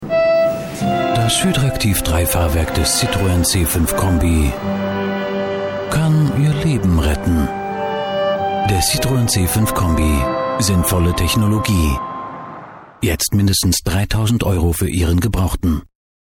CitroenHydractivTVSpot.mp3